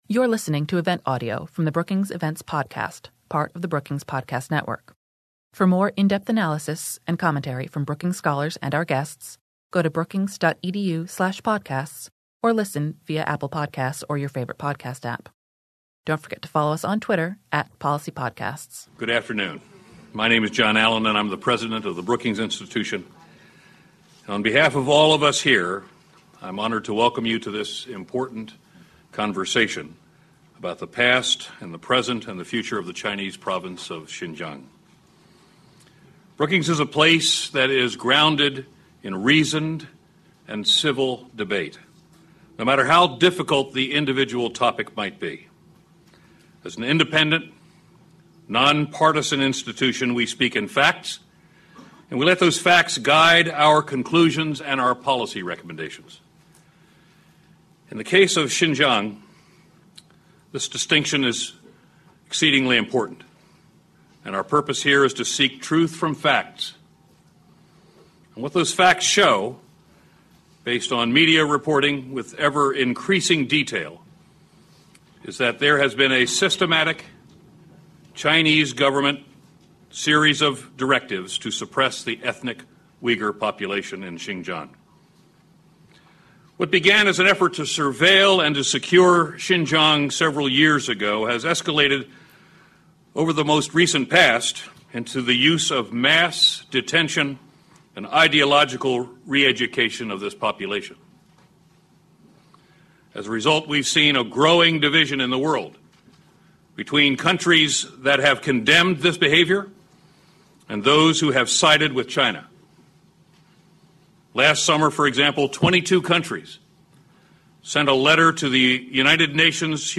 On January 16, the Foreign Policy program at Brookings convened a panel of experts to discuss the past, present, and future of China’s Xinjiang policies. Brookings President John R. Allen opened the discussion and provide framing remarks about events in Xinjiang.
A panel of experts examined all aspects of China’s approach to Xinjiang and offer perspectives on how the United States and the rest of the world might respond. Following the conversation, panelists answered questions from the audience.